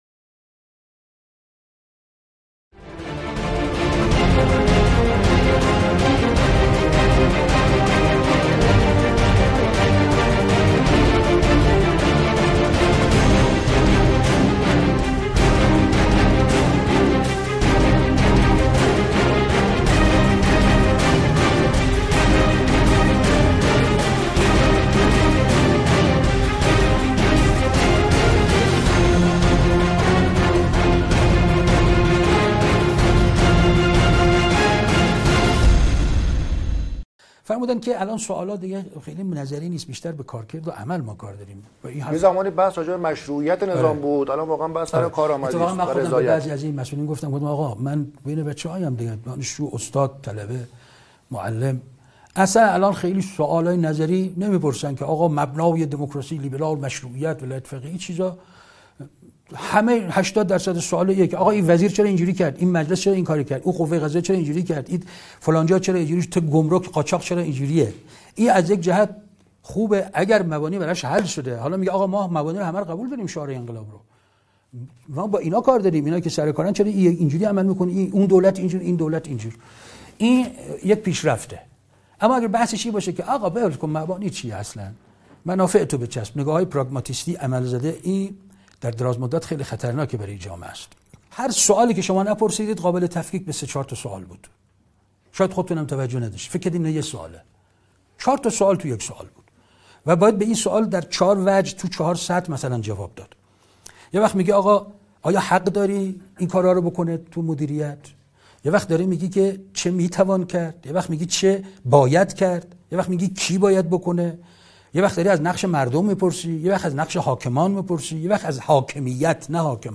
برنامه كامل گفتگوی برخی فعالان رسانه‌ای با استاد حسن رحیم‌پور ازغدی | قسمت پنجم